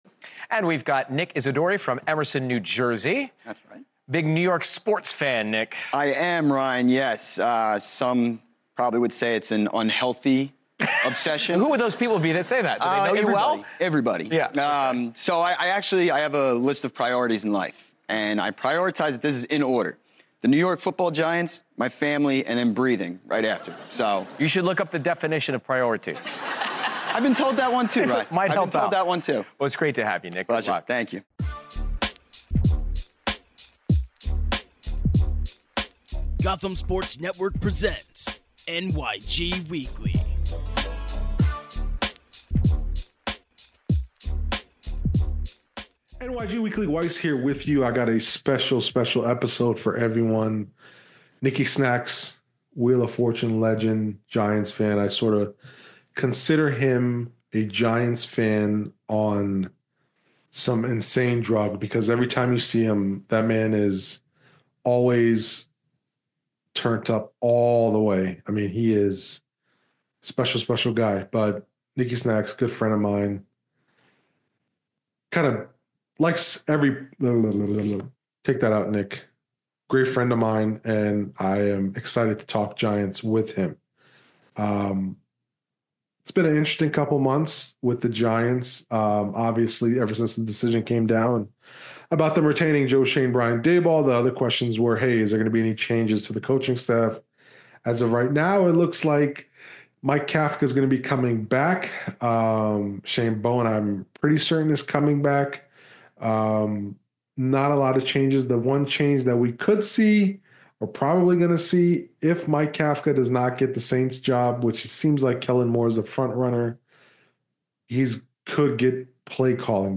a wide ranging conversation about the state of the New York Giants